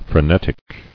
[fre·net·ic]